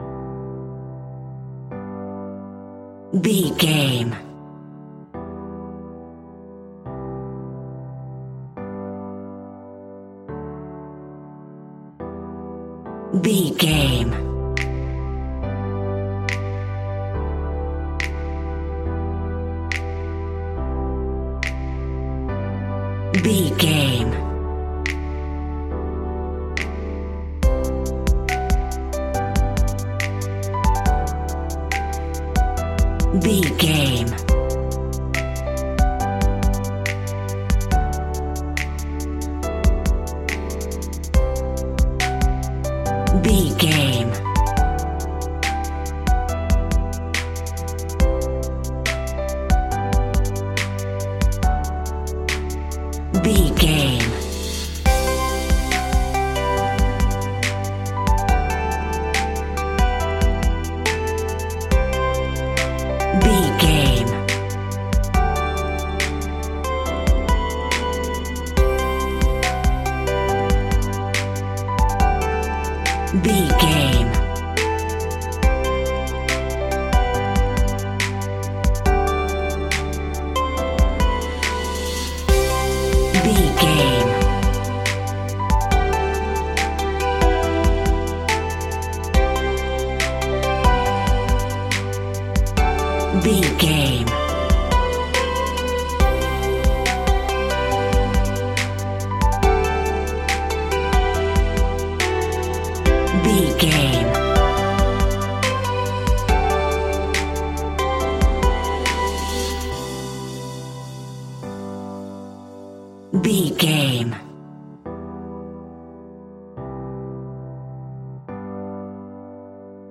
Ionian/Major
Slow
instrumentals
chilled
laid back
groove
hip hop drums
hip hop synths
piano
hip hop pads